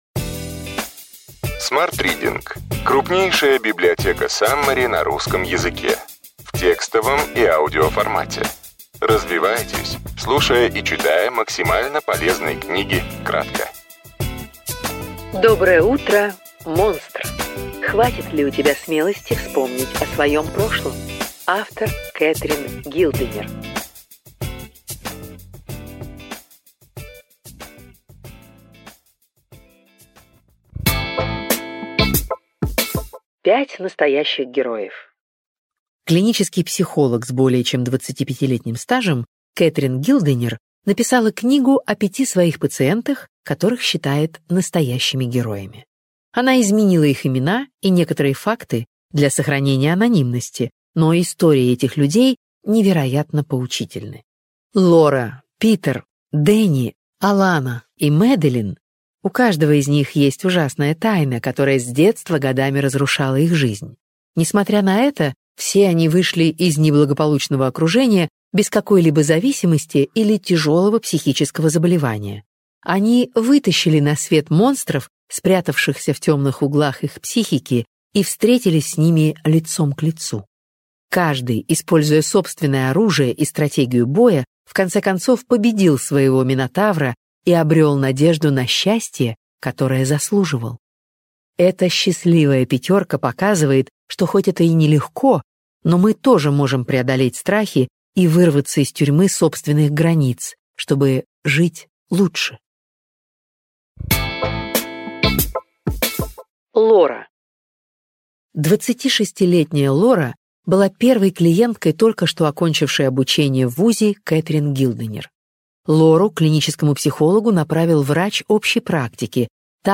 Аудиокнига Доброе утро, монстр!